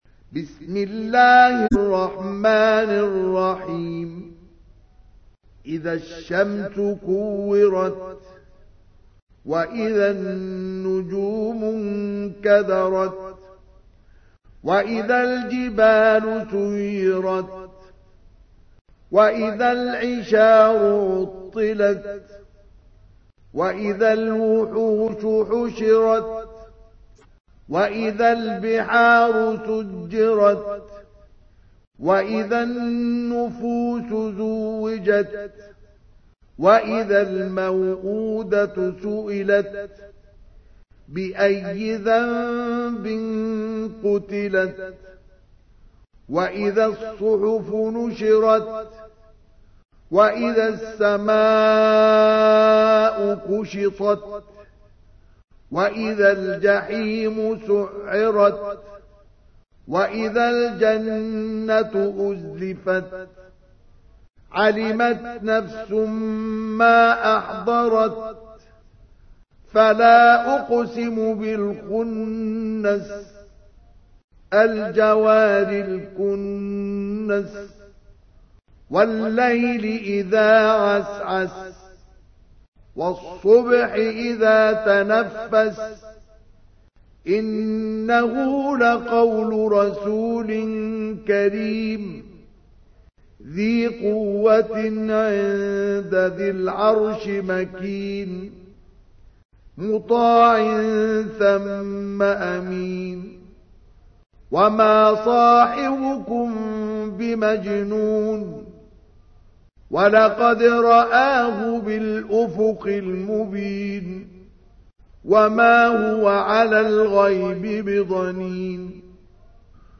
تحميل : 81. سورة التكوير / القارئ مصطفى اسماعيل / القرآن الكريم / موقع يا حسين